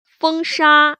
[fēngshā] 펑사